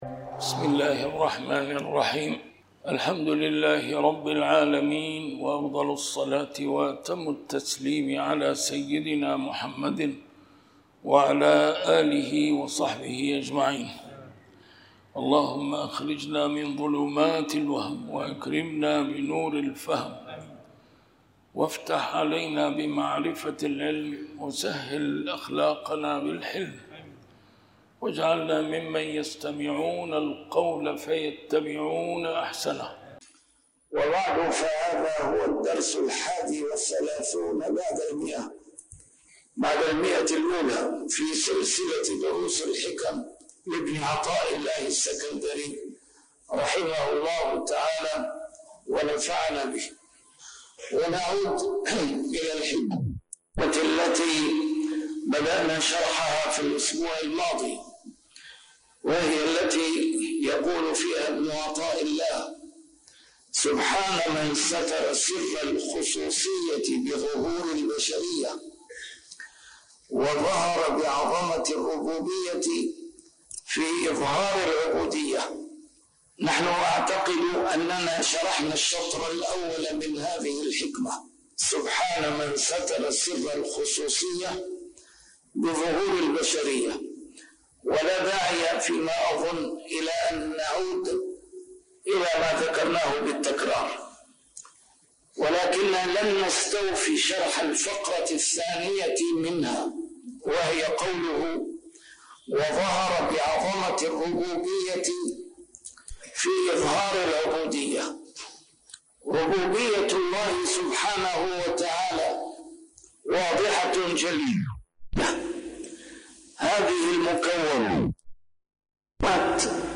A MARTYR SCHOLAR: IMAM MUHAMMAD SAEED RAMADAN AL-BOUTI - الدروس العلمية - شرح الحكم العطائية - الدرس رقم 131 شرح الحكمة 108+109